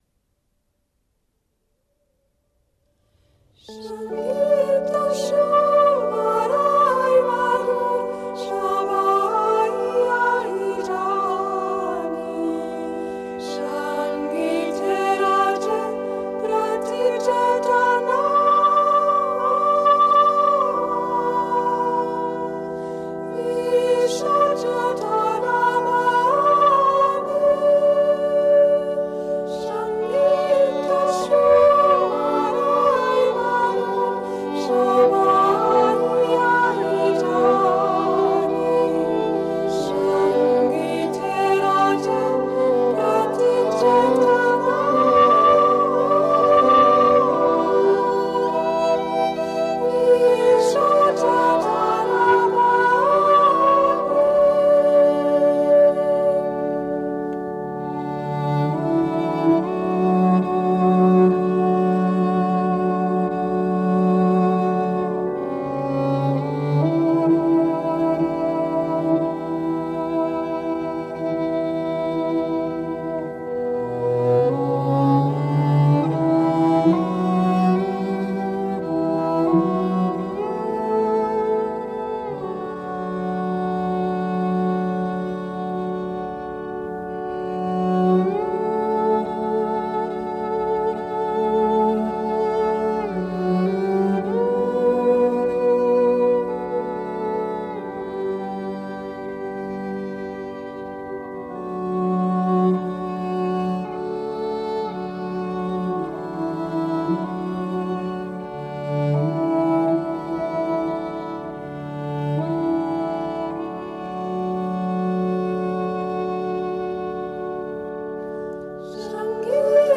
is female group from Germany
who combine a western musical style with the meditative flow